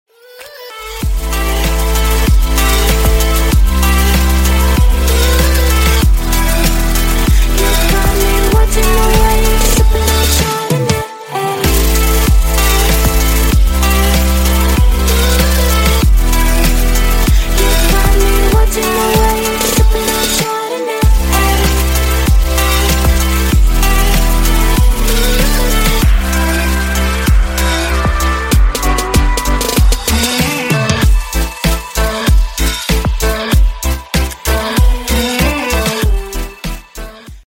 Клубные Рингтоны » # Громкие Рингтоны С Басами
Рингтоны Электроника